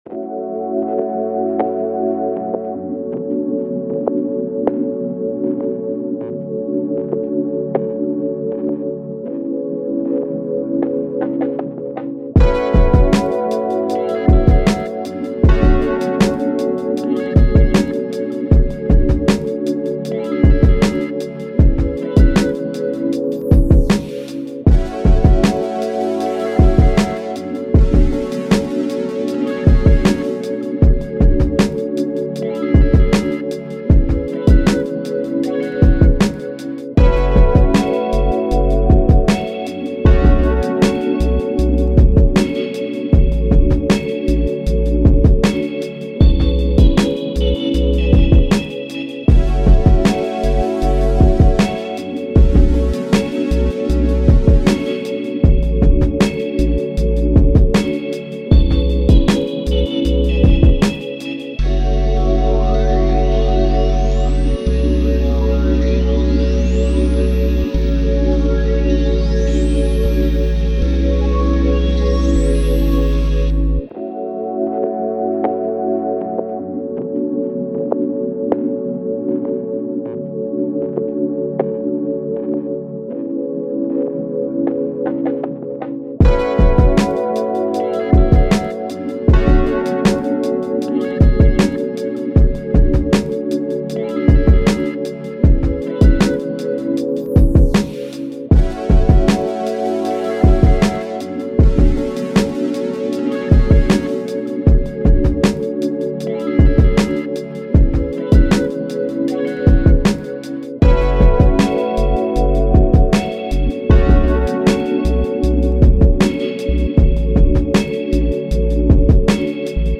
Фоновая музыка для видео о кулинарии